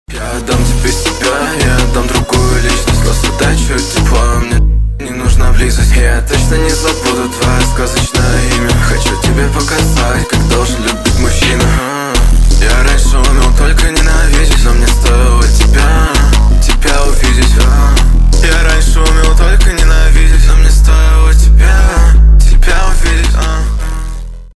лирика , рэп